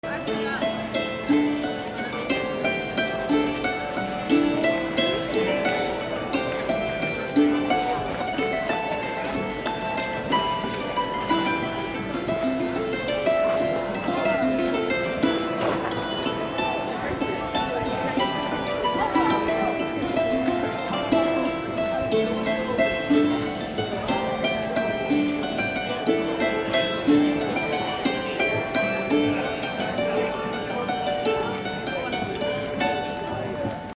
on hammered dulcimer at Scarborough Ren Faire